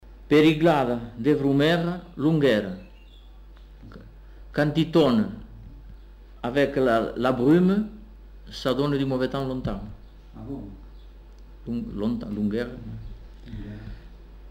Aire culturelle : Comminges
Lieu : Bagnères-de-Luchon
Effectif : 1
Type de voix : voix d'homme
Production du son : récité
Classification : proverbe-dicton